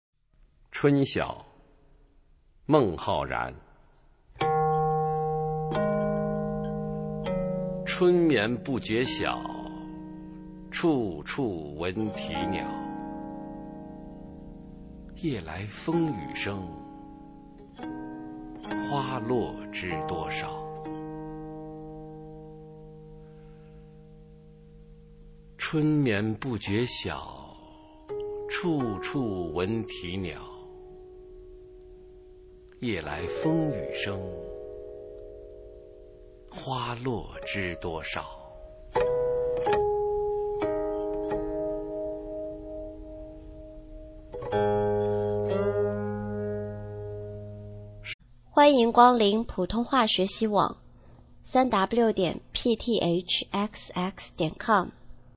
普通话美声欣赏：春晓　/ 佚名